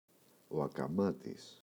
ακαμάτης [akaꞋmatis] – ΔΠΗ